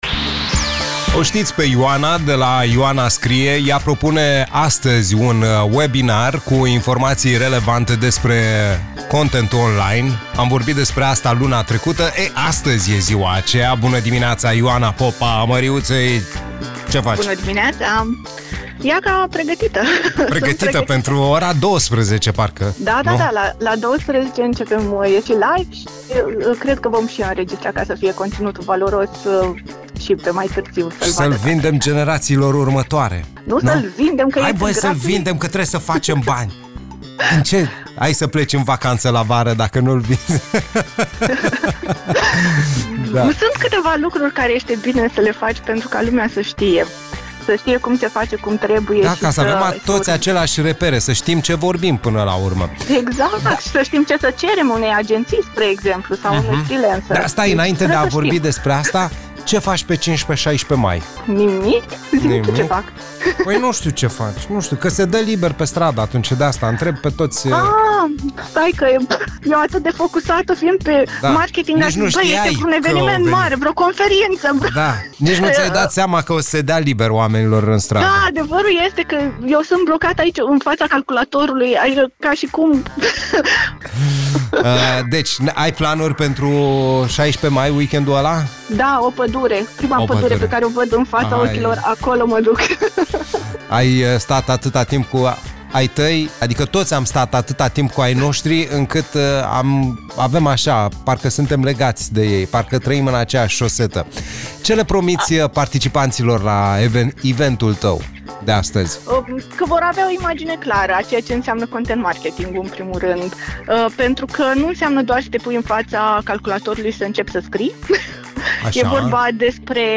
Astăzi ne-a dat câteva detalii la Play the Day despre Webinarul ei și cum să ne creștem afacerea.